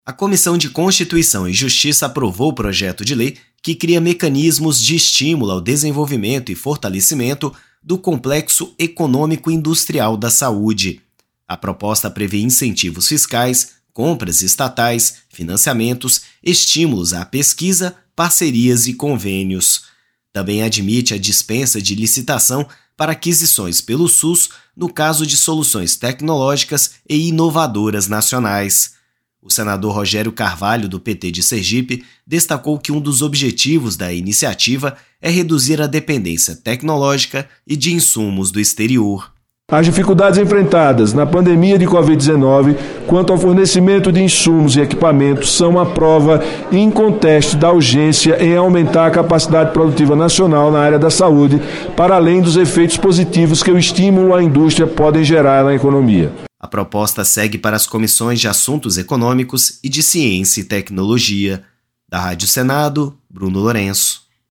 O senador Rogério Carvalho (PT-SE), que é médico, diz que um dos objetivos da iniciativa é reduzir dependência de insumos do exterior.